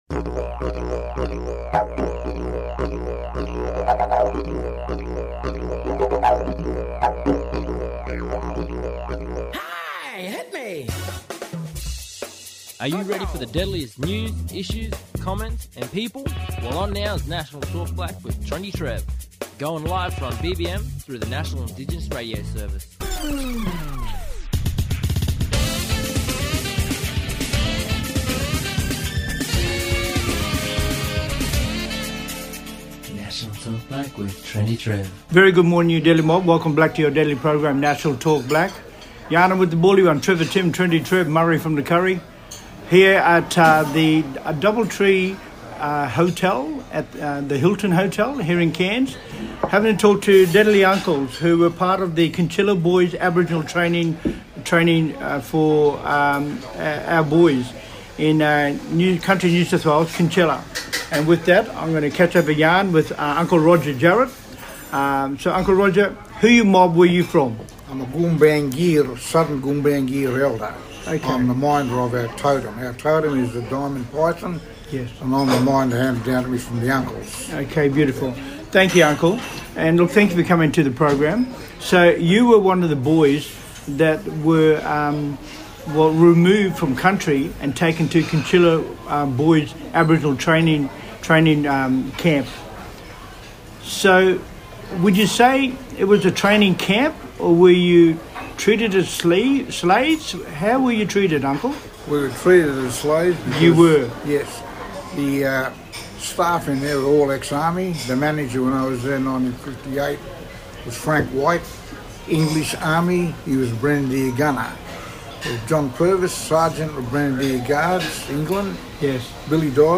Special Guests from the Kinchela Boys Home Aboriginal Corporation, Stolen Generations Survivors, talking about their Truth Telling and Cultural Celebration Exchange Event. The Boys home invited the community to come together to honour and celebrate the resilience and stories of the Kinchela Boys Home Elders, as they visit Gimuy for the first time.